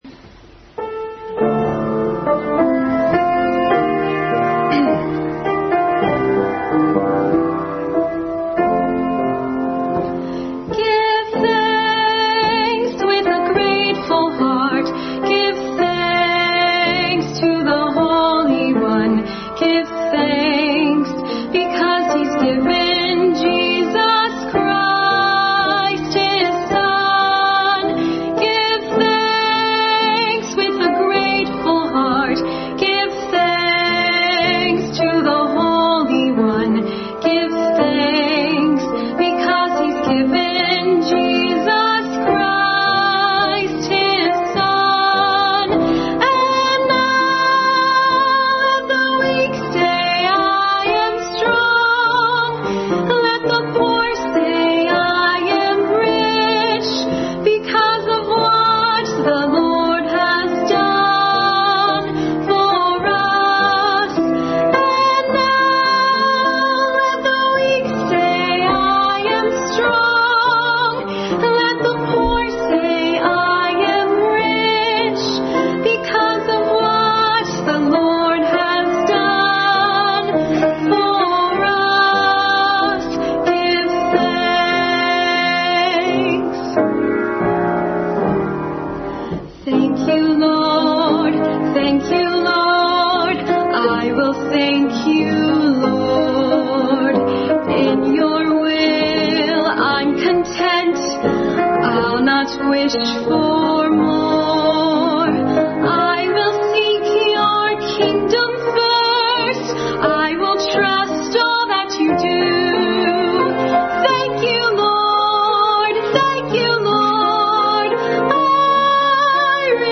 Family Bible Hour message preceded by special music.